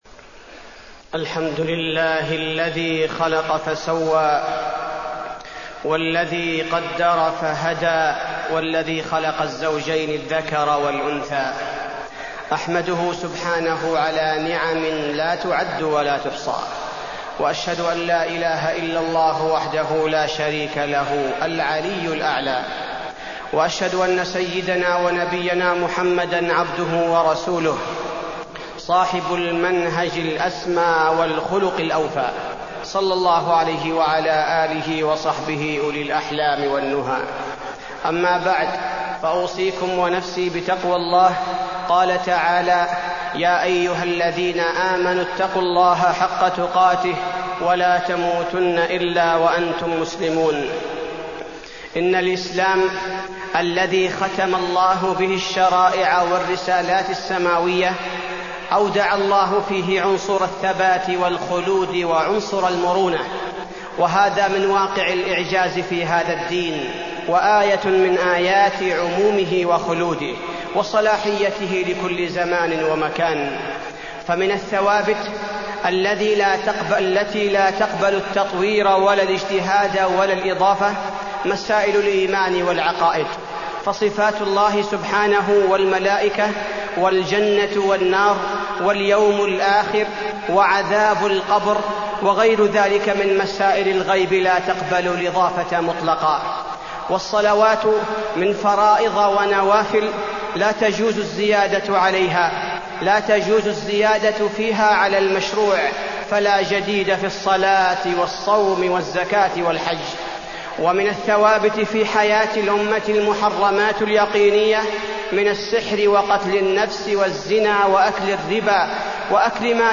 تاريخ النشر ٢٢ محرم ١٤٢٣ هـ المكان: المسجد النبوي الشيخ: فضيلة الشيخ عبدالباري الثبيتي فضيلة الشيخ عبدالباري الثبيتي الثوابت والمتغيرات المعاصرة